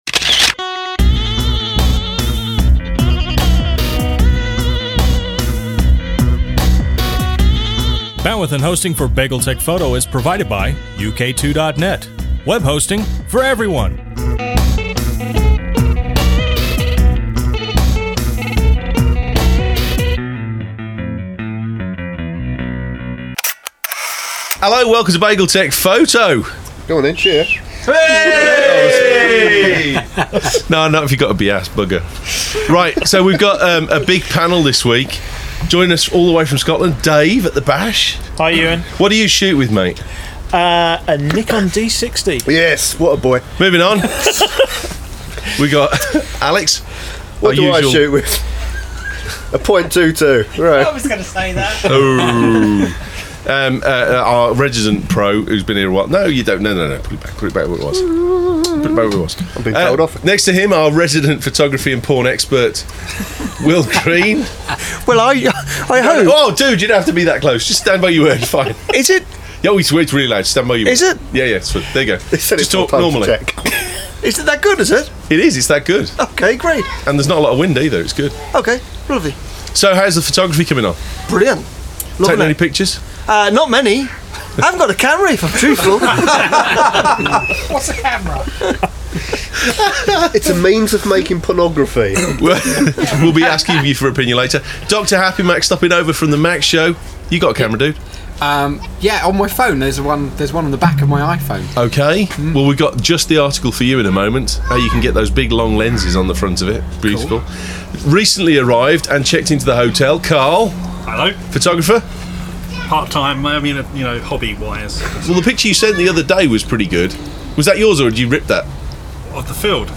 The Bash was in full swing and all the usual suspects from the Mac Show stayed over for the Foto Show and a good time was had by all.